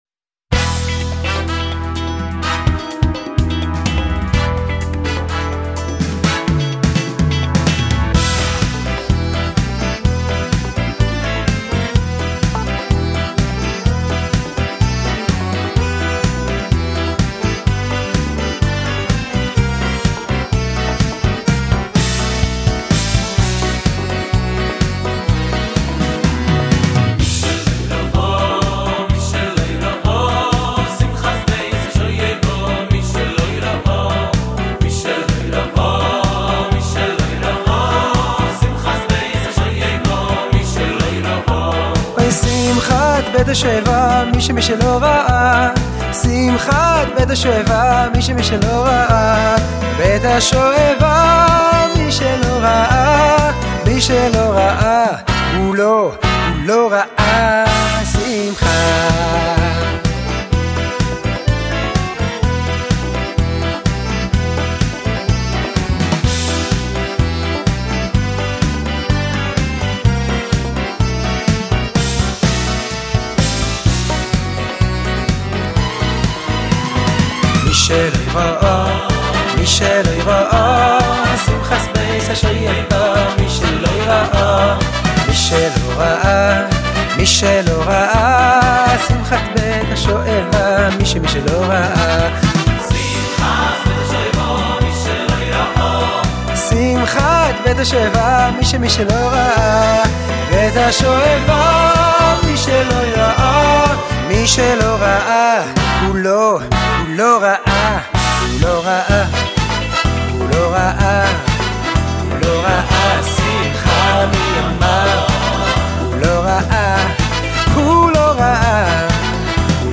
שיר חסידי
מקהלה של חסידים אמיתיים